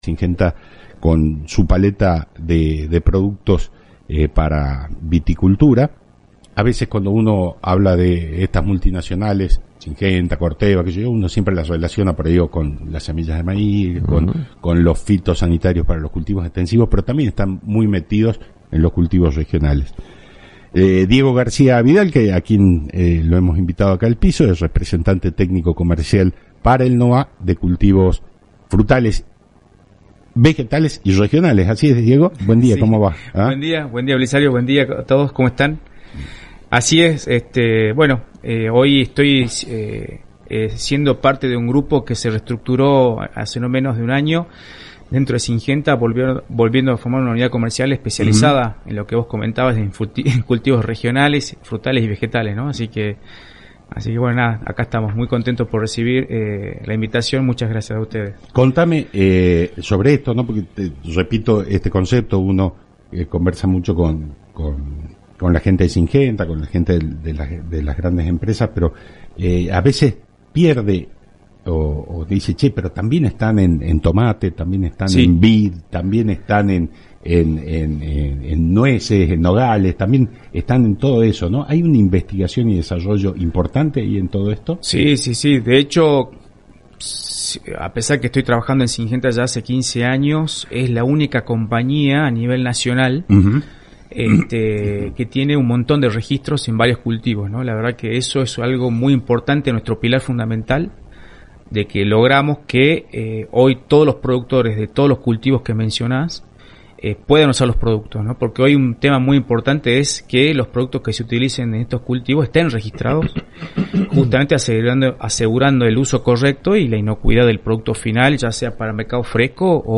En diálogo con Claves del Campo (AM 840 – Radio Salta)